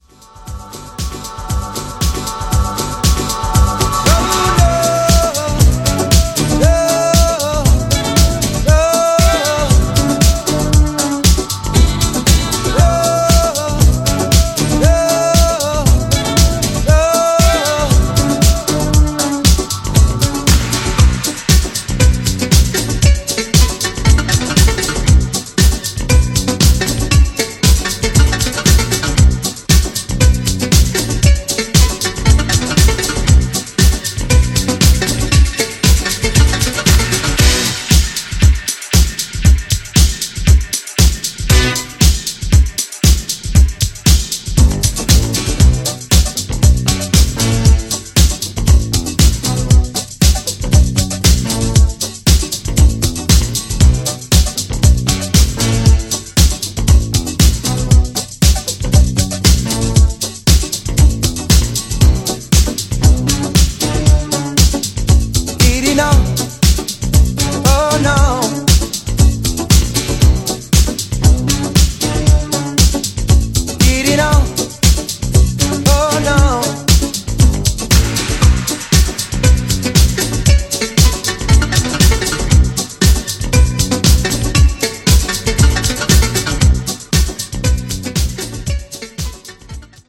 ジャンル(スタイル) NU DISCO / BALEARIC